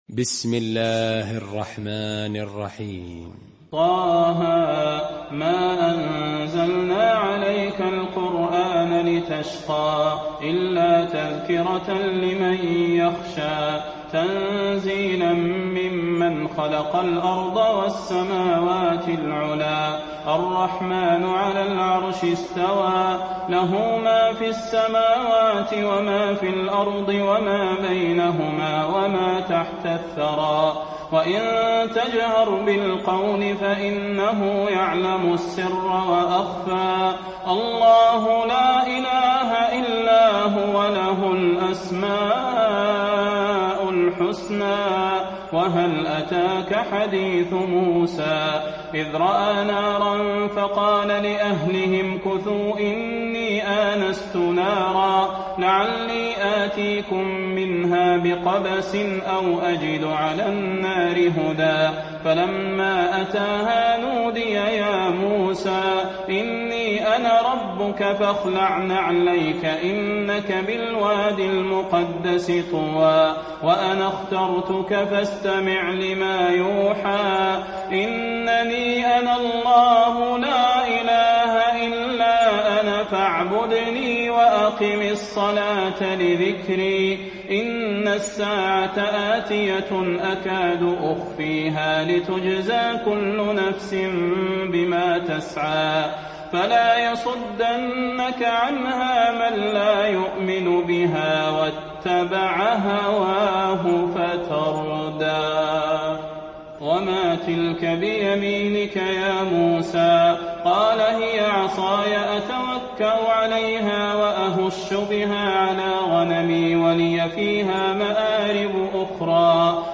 المكان: المسجد النبوي طه